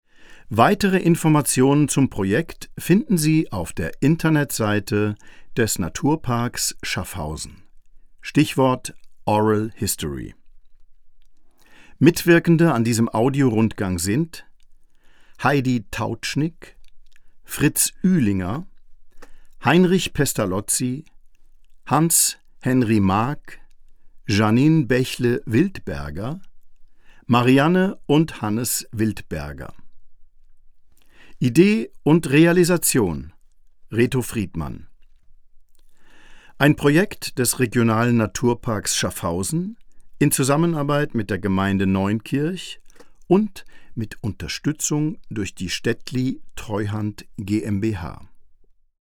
Dazu sollen Zeitzeugen, die mit den Gebäuden in Verbindung stehen, zu Wort kommen. In Tonaufnahmen mit Hilfe der Methode «Oral History» sollen diese Menschen zu ausgewählten Bauwerken erzählen und ihre persönlichen Erinnerungen und Erlebnisse teilen.